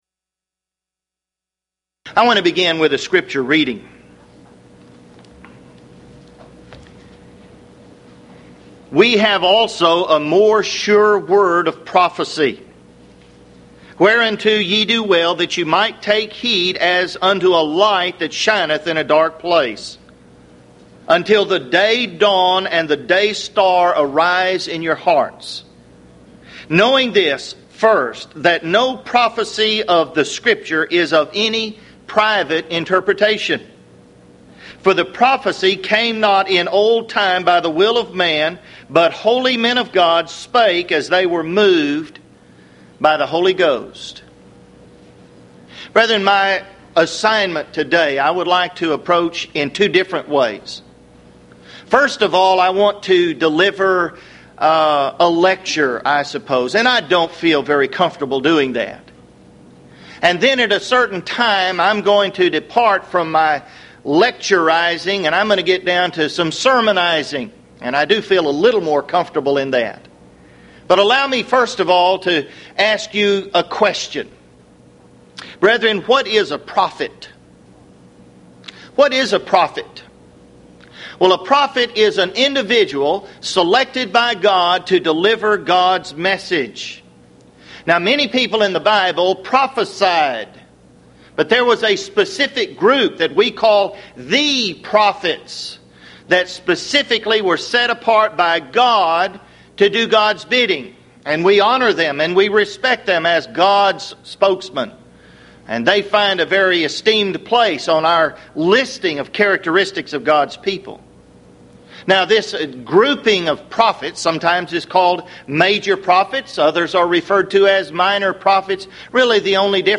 Houston College of the Bible Lectures